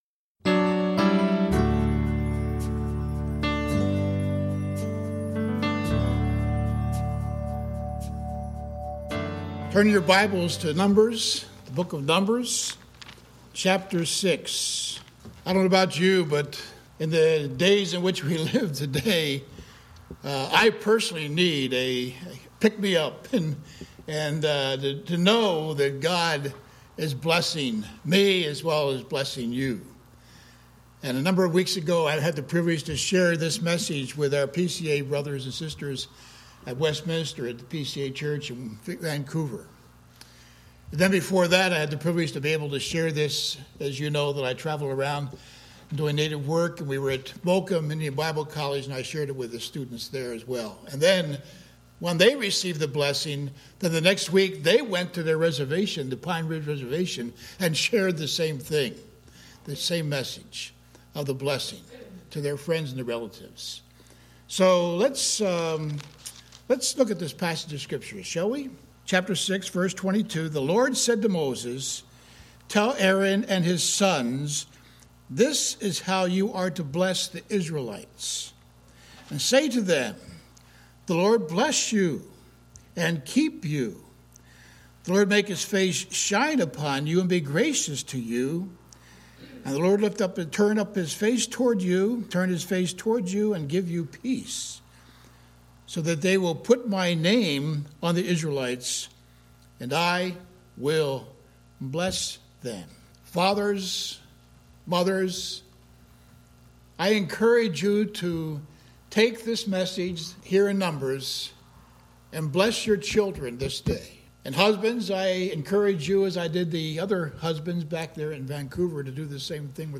Sermon audio and video are posted.